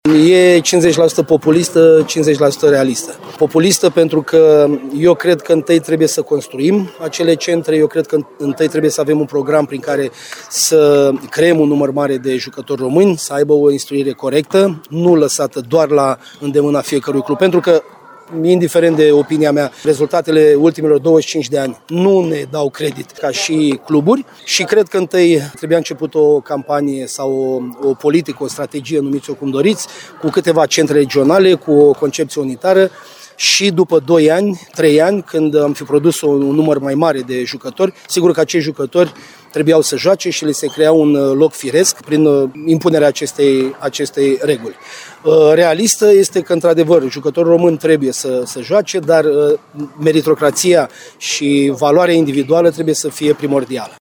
Decizia a fost comentată de doi foști selecționeri, la capătul partidei SCM Timișoara – CSM Galați 85-81.